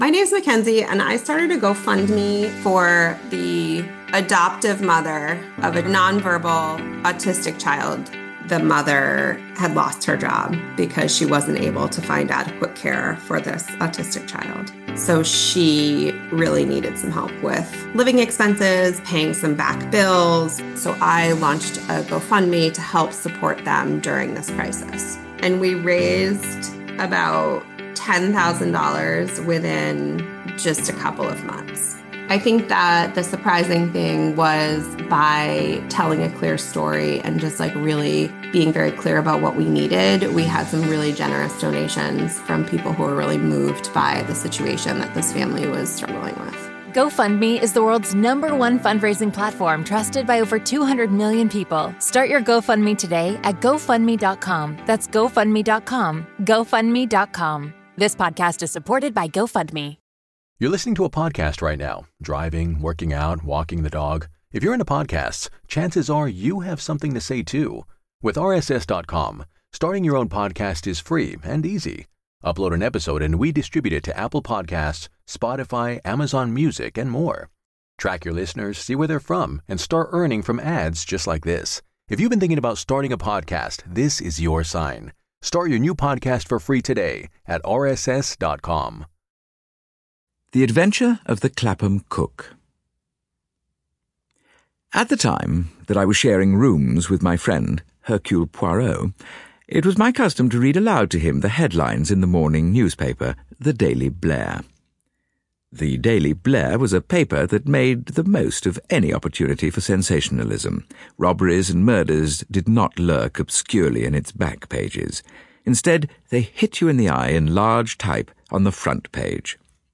Agatha Christie - Hercule Poirot (Audiobook Collection) Podcast - Agatha Christie - Hercule Poirot 10 - The Adventure of the Clapham Cook (1920) | Free Listening on Podbean App